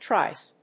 /trάɪs(米国英語)/